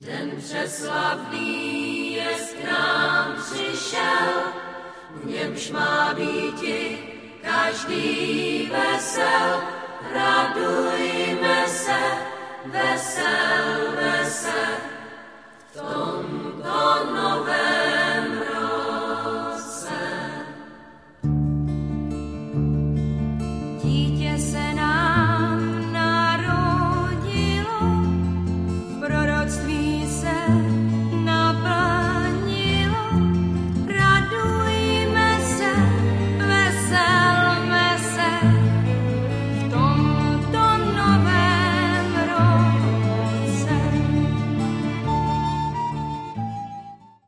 Dívčí country kapela, založená původně již roku 1971.
vánoční koledy